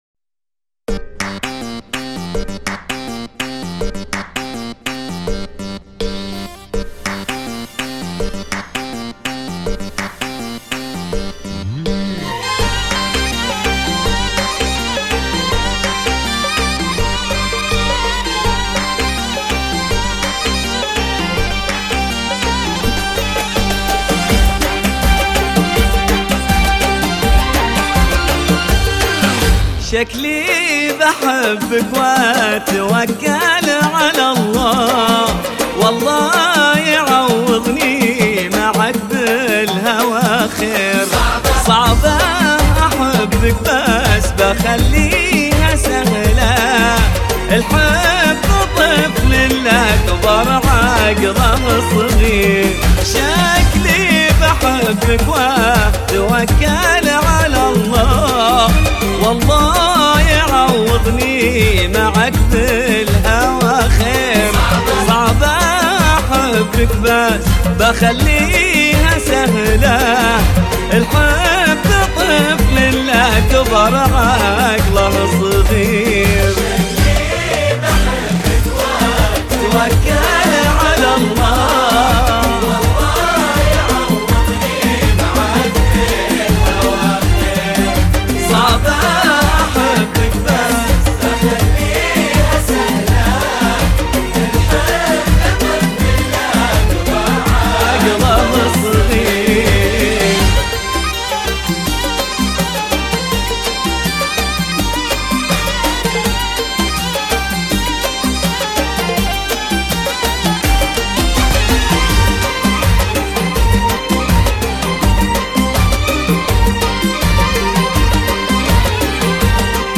اغاني هجوله خليجيه